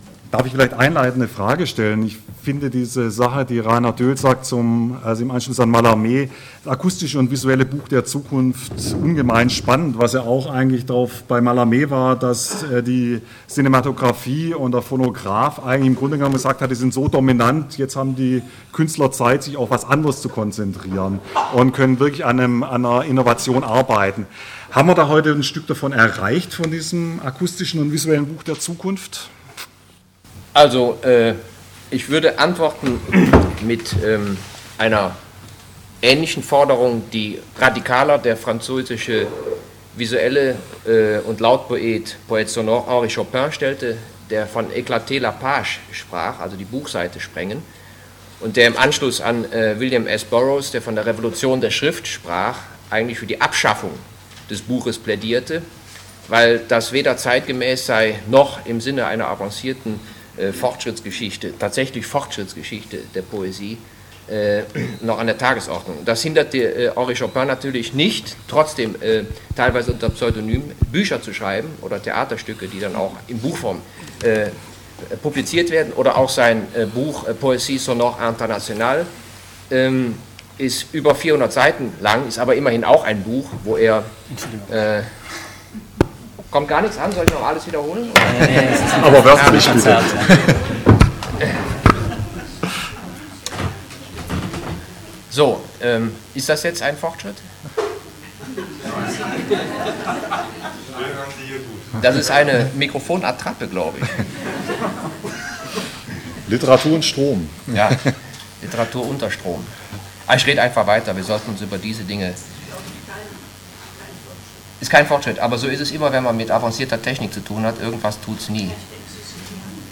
konkret, digital, doehl - Gespträch
Literatur und Strom 3: Code Döhl - Literaturhaus Stuttgart 27.-29. Mai 2009 Reinhard Döhl